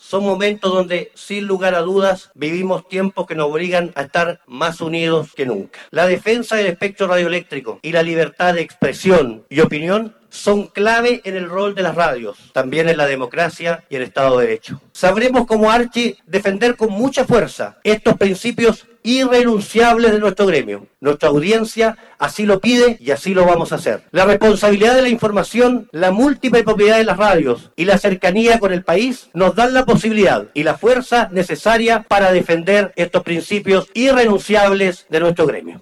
Presidente Piñera encabezó la ceremonia inaugural de la Asamblea ARCHI 2021 - RadioSago